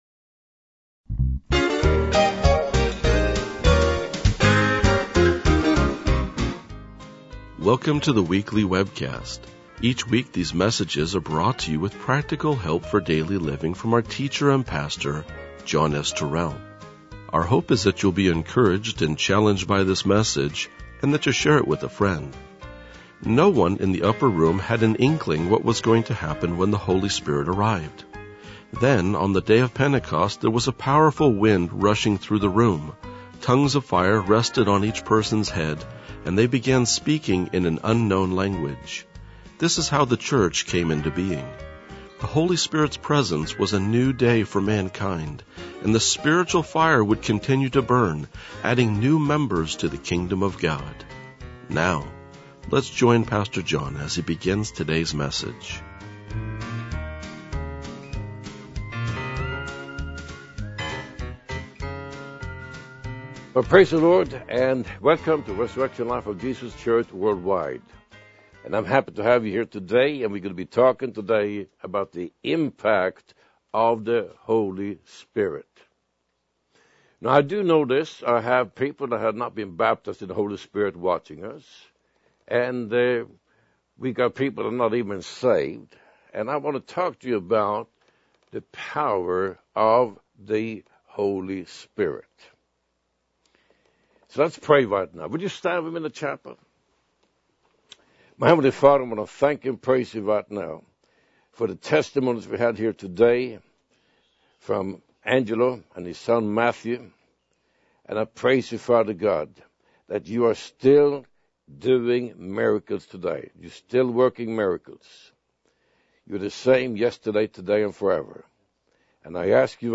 RLJ-1999-Sermon.mp3